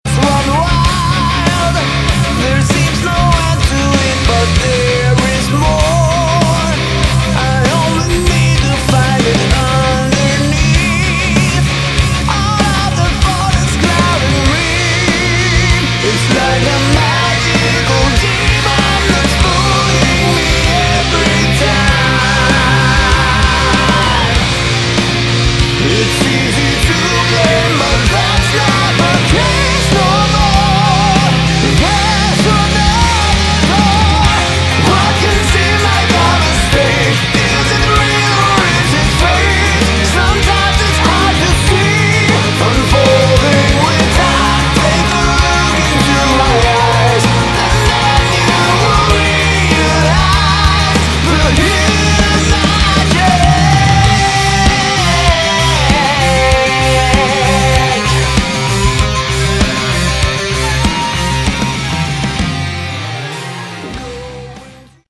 Category: Melodic Rock / AOR
vocals, backing vocals
guitar, bass, synthesizer, backing vocals
drums, percussion
keyboards
piano
flute, backing vocals